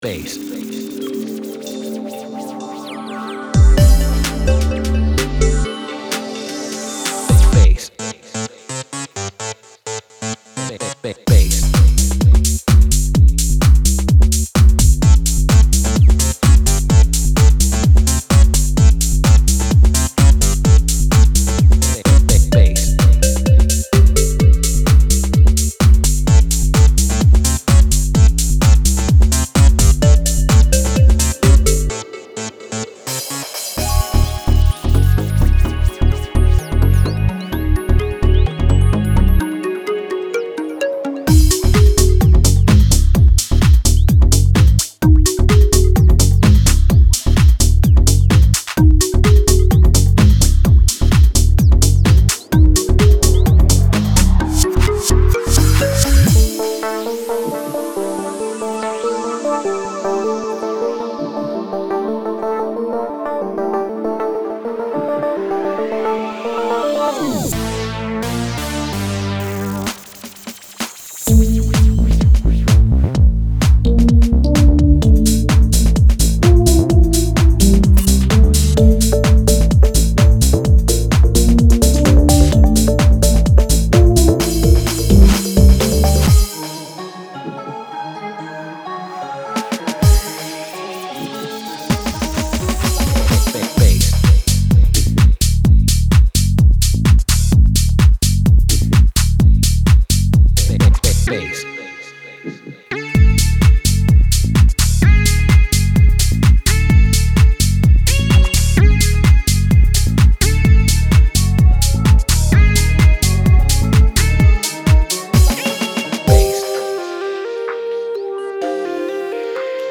All loops play at 128bpm.
Demo